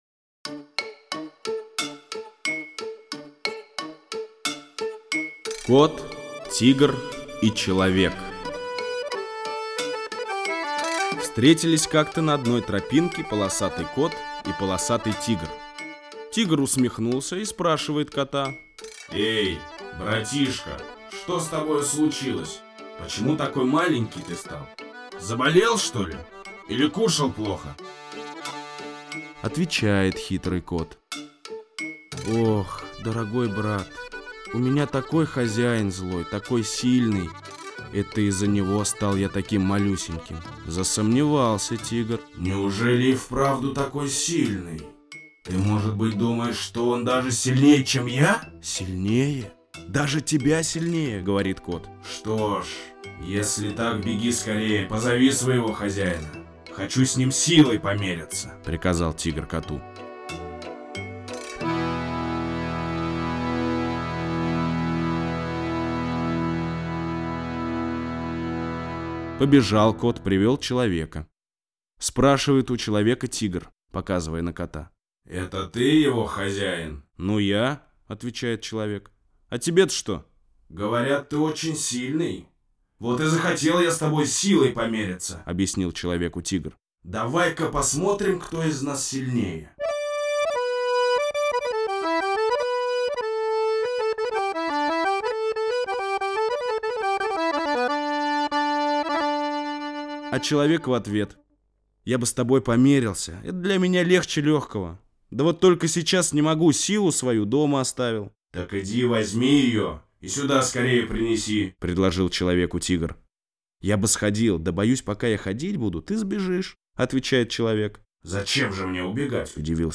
Кот, Тигр и Человек - татарская аудиосказка - слушать онлайн